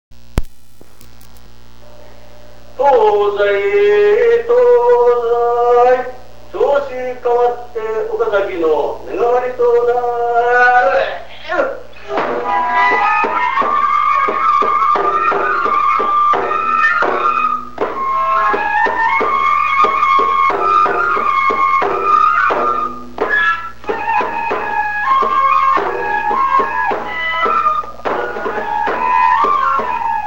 神楽囃子は昔、１４種類あったが今は１１種類で３種類は幻の曲になってしまった。
獅子舞のお囃子